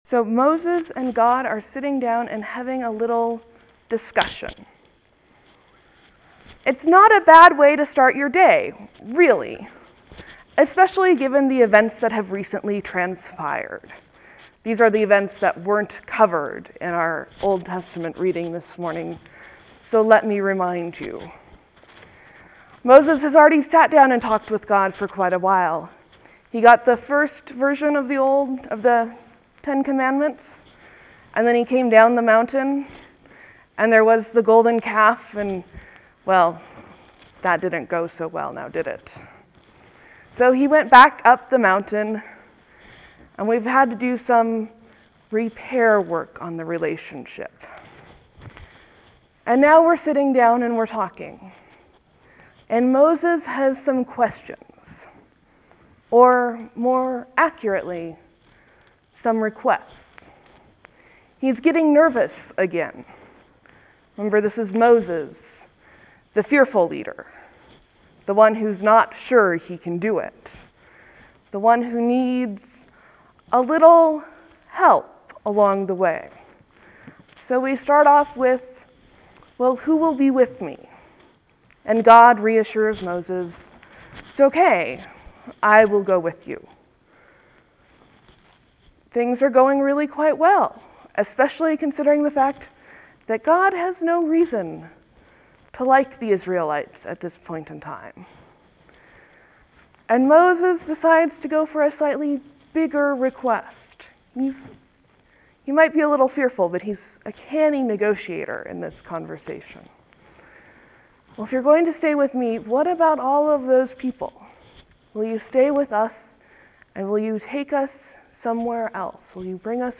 Sermon, Leave a comment
(There will be a few moments of silence before the sermon starts.  Thank you for your patience.)